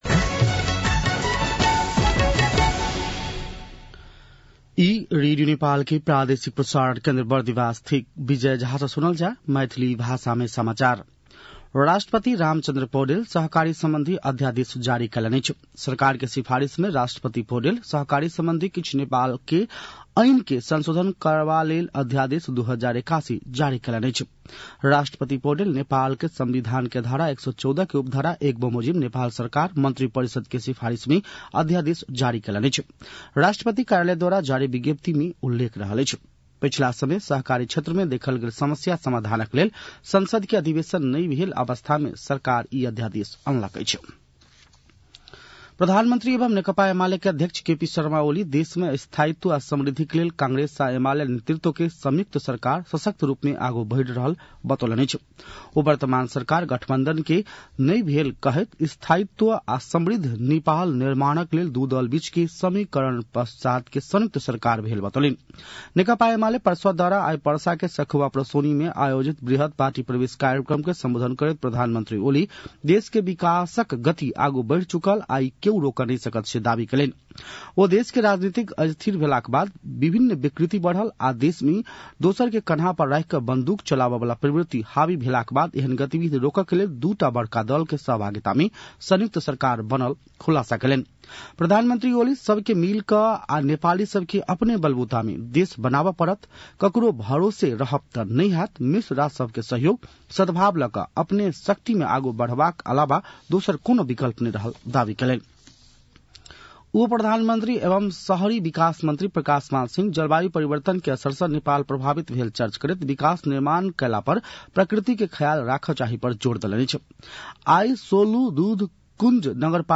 मैथिली भाषामा समाचार : १५ पुष , २०८१
Maithali-News-9-14.mp3